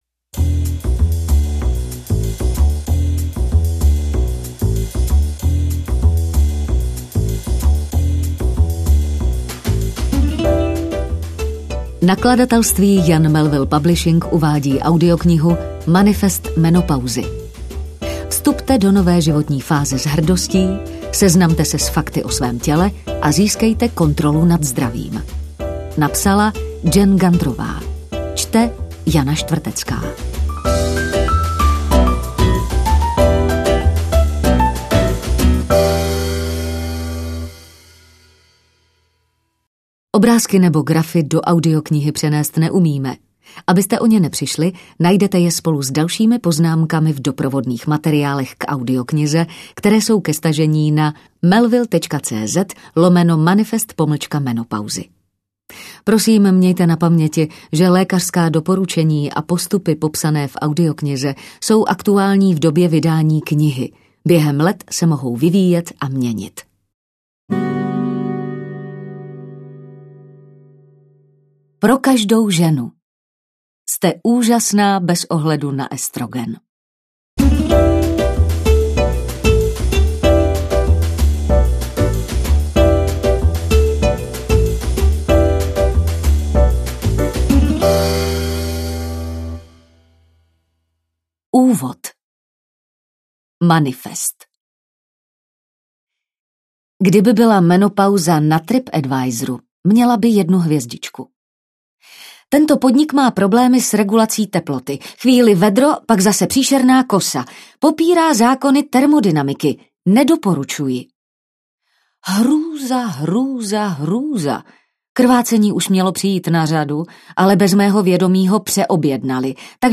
Audiokniha Manifest menopauzy - Jen Gunterová | ProgresGuru